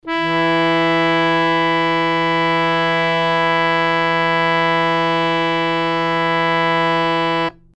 interactive-fretboard / samples / harmonium / E3.mp3
E3.mp3